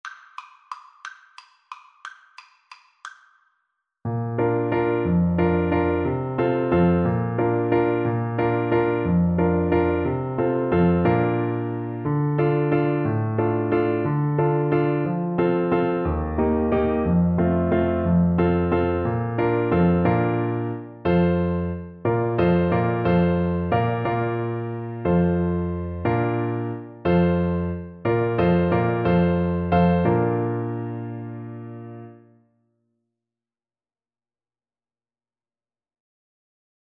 Free Sheet music for Clarinet
Clarinet
Bb major (Sounding Pitch) C major (Clarinet in Bb) (View more Bb major Music for Clarinet )
3/4 (View more 3/4 Music)
bring_a_torch_CL_kar1.mp3